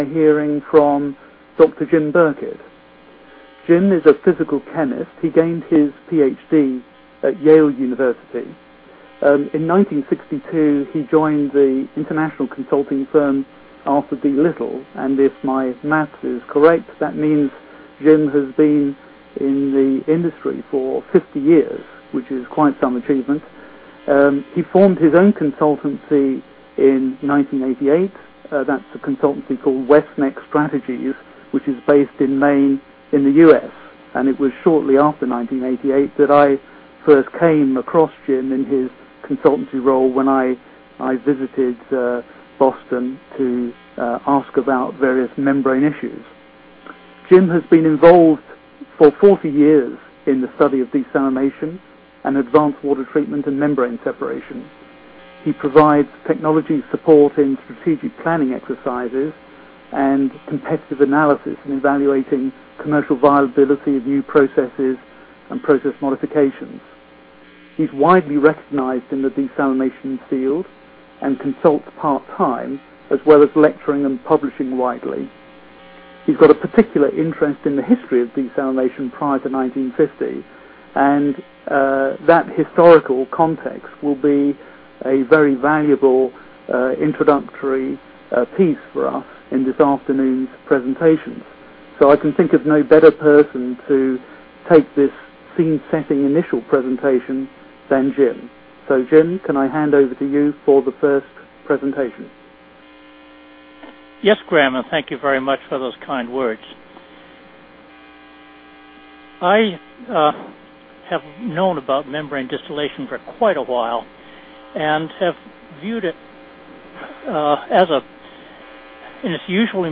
BlueTech Research is pleased to announce the details of the next interactive web-conference in the BlueTech® Webinar Series entitled: Membrane Distillation: after 40 years of research, is MD about to make an impact?